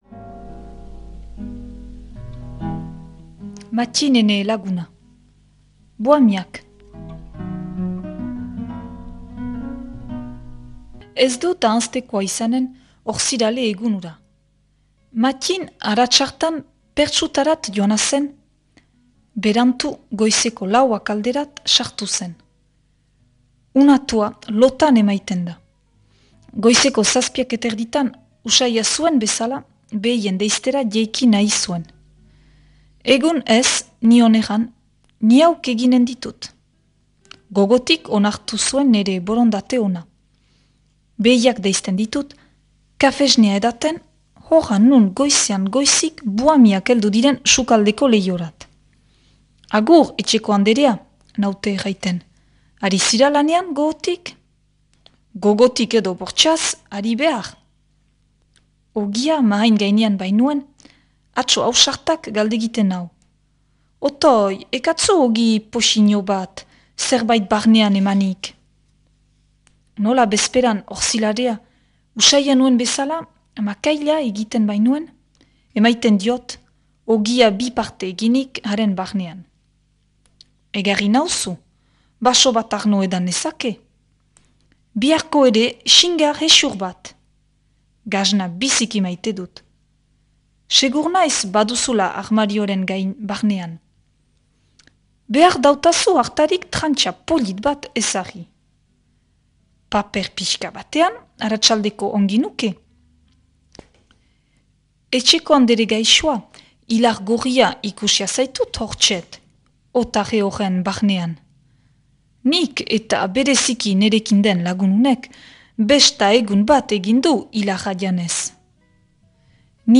irakurketa da.